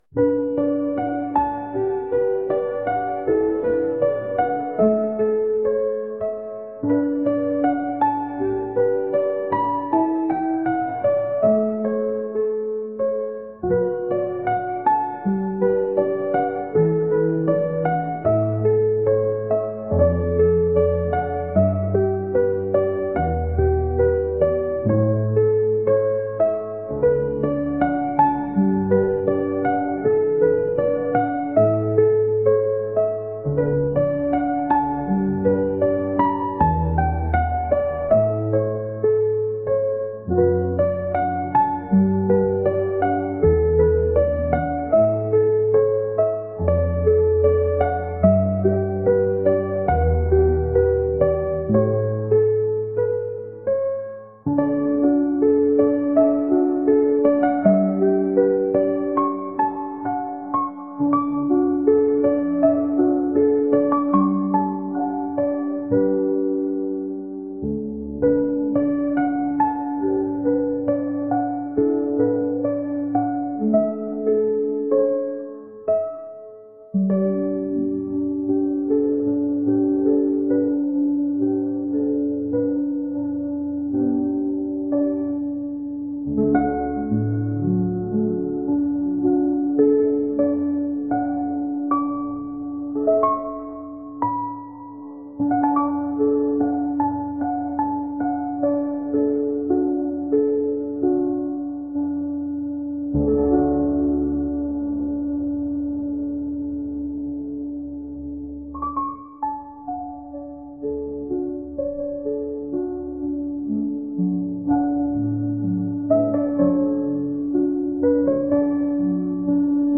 癒し、リラックス